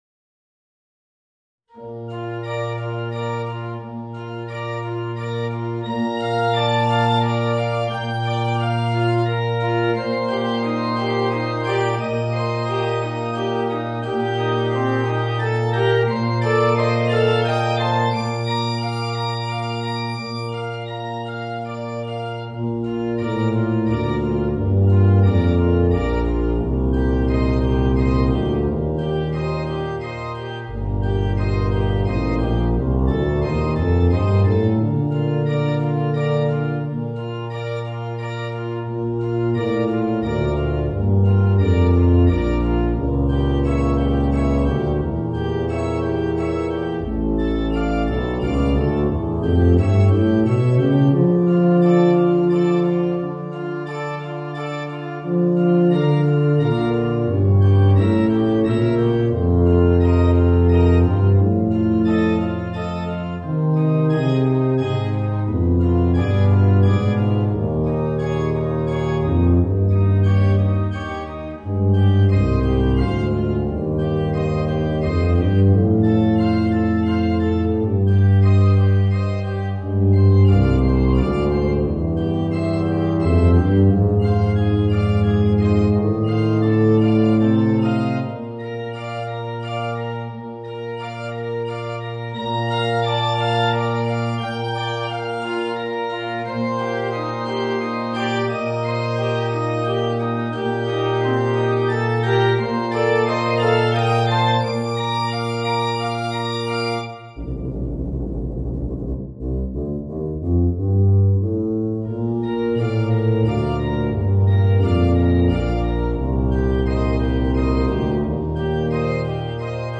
Voicing: Tuba and Organ